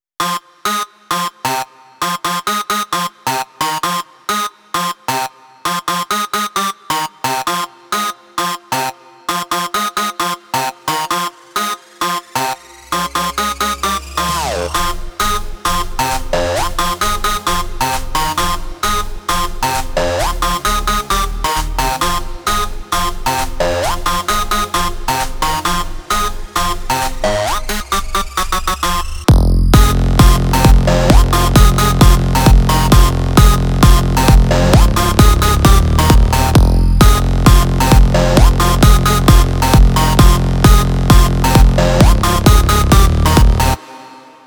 שמעתי --ממש יפה וסאונד מצוין